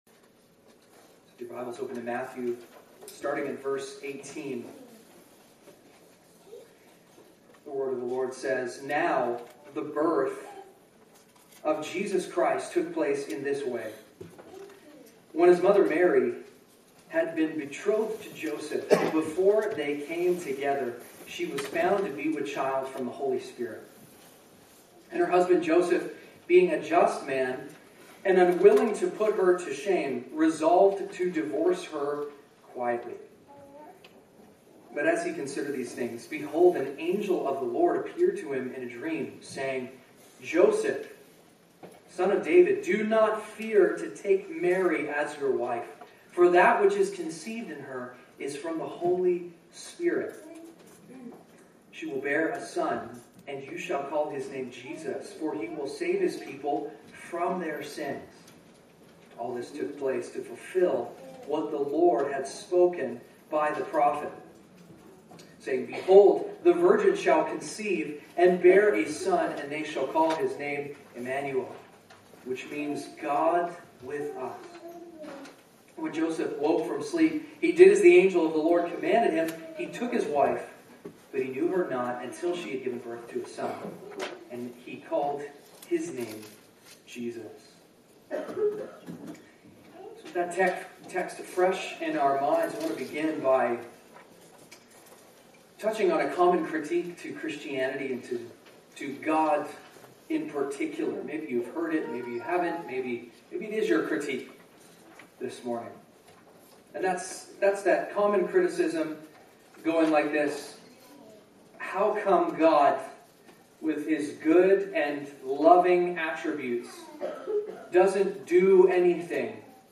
preaches from Matthew 1:18-25. Passage: Matthew 1:18-25…